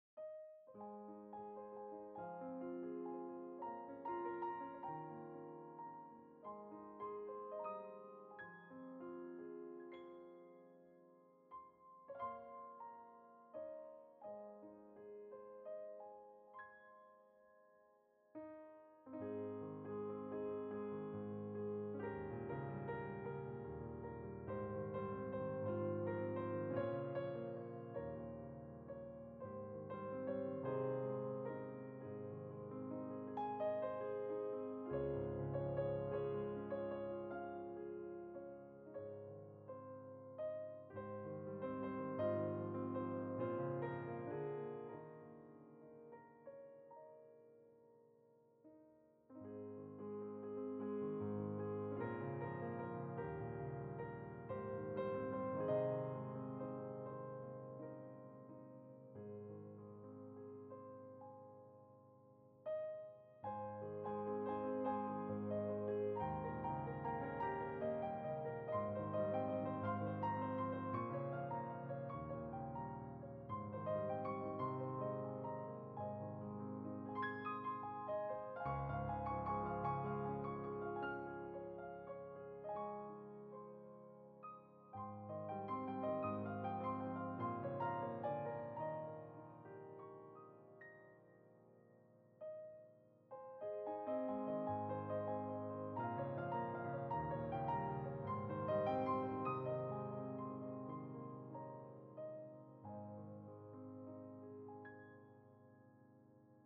Musique : Le temps des cerises - piano - 1,52 Mo - 1 mn 46